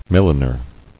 [mil·li·ner]